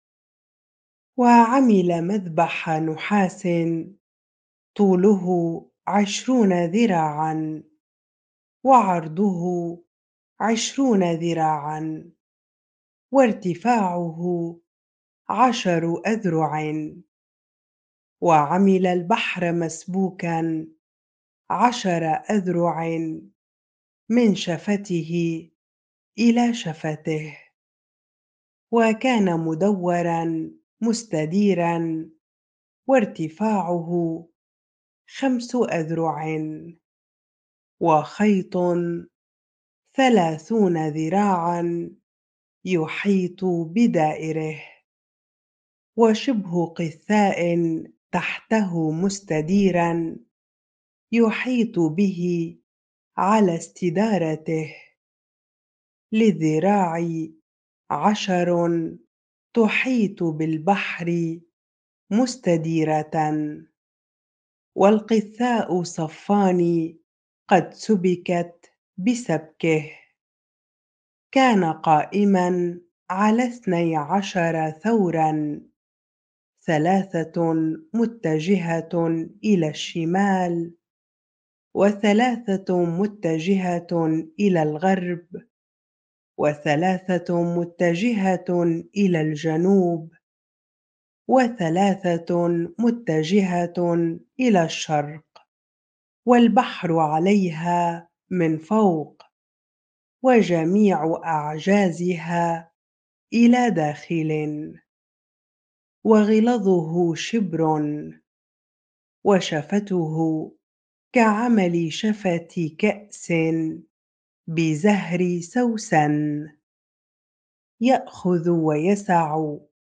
bible-reading-2 Chronicles 4 ar